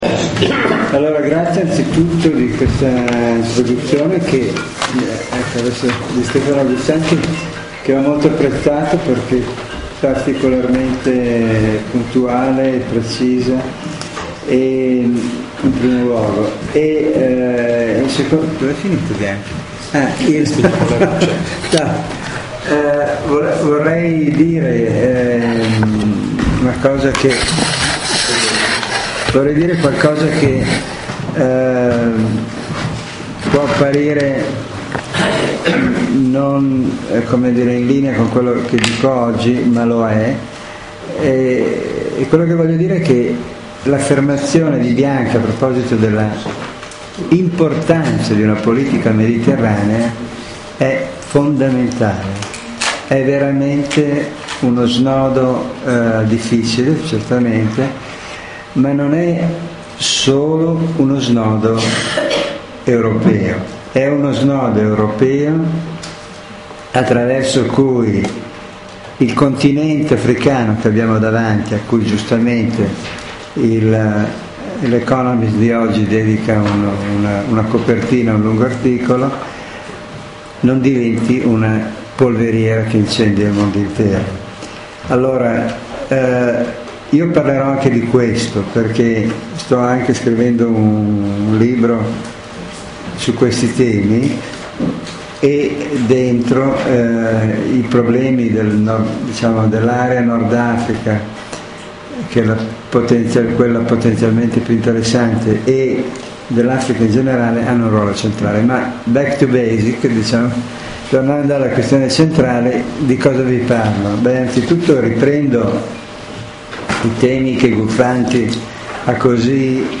premessa di Giovanni Bianchi